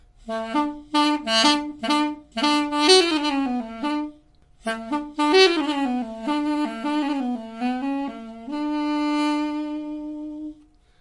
描述：由Duduk缩放 亚美尼亚双簧管乐器录音机：Zoom H4n Sp数字便携式录音机Studio NICS UNICAMP
标签： 埃斯卡拉 杜读管 亚美尼亚
声道立体声